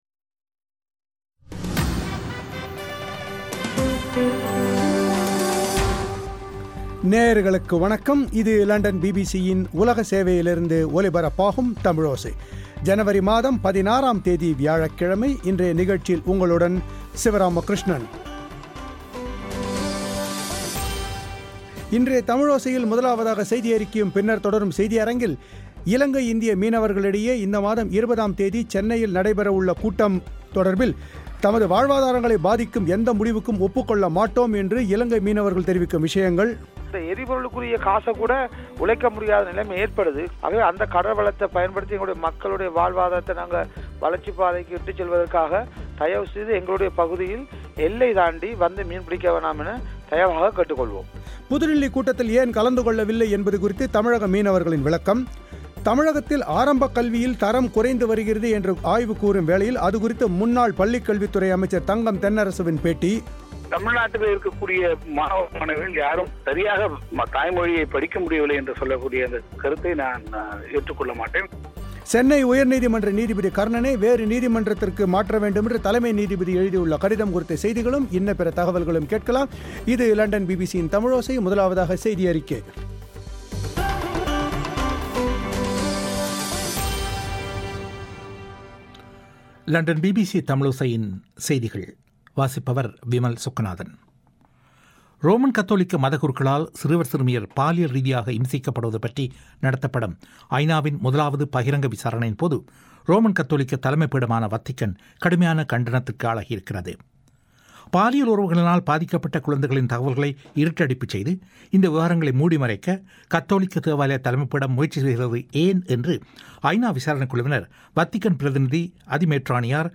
தமிழகத்தில் ஆரம்பக் கல்வியில் தரம் குறைந்து வருகிறது என்று ஆய்வு கூறும் வேளையில் அது குறித்து முன்னாள் பள்ளிக்கல்வி அமைச்சர் தங்கம் தென்னரசுவின் பேட்டி